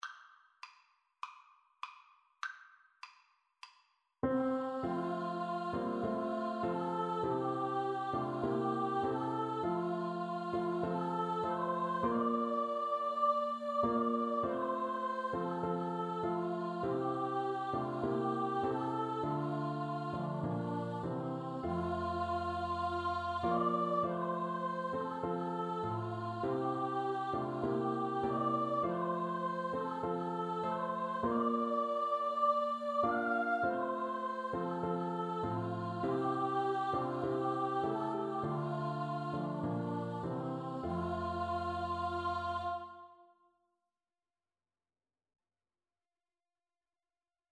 Free Sheet music for Choir (SATB)
Scottish